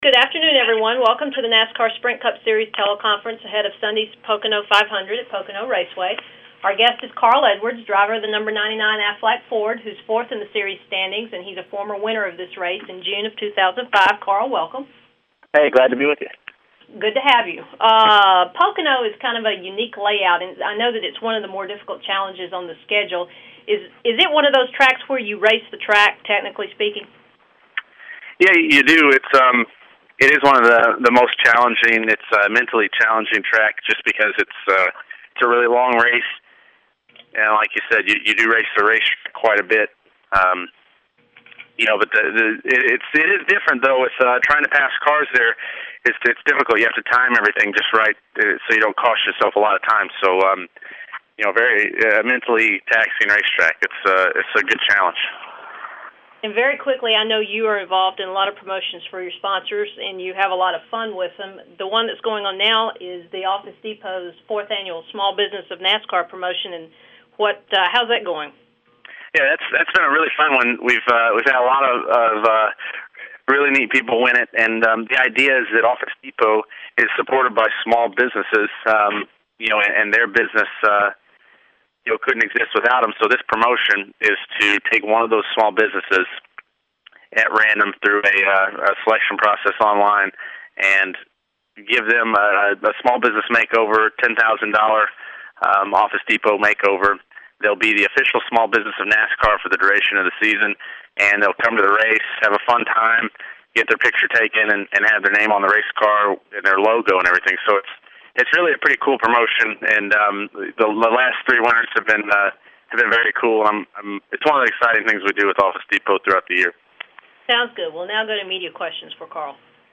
This week, Carl joined the NASCAR press conference and talked about the upcoming races at Eldora and Pocono, plus a report that Roush-Fenway teammate Jamie McMurray from Joplin, may be looking for a new team next year.